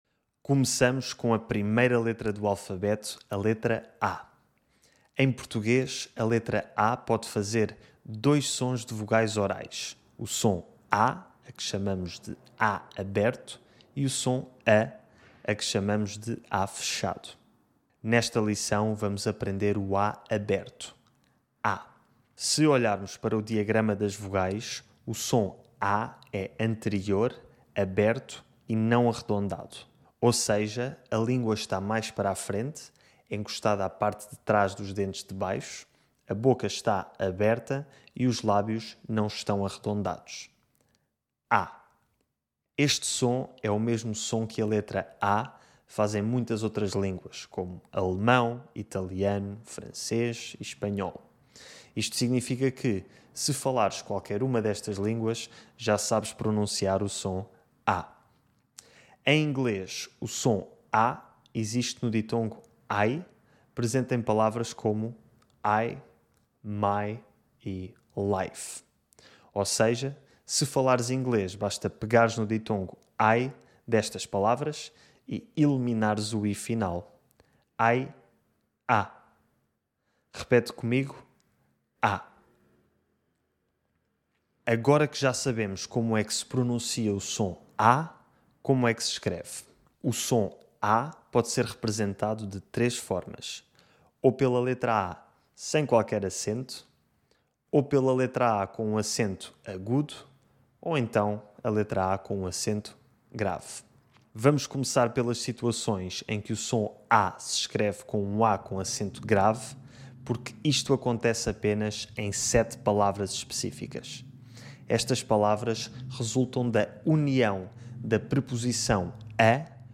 2.2 - O som [a] - A aberto
Master the Portuguese accent and learn how to speak European Portuguese like a native speaker!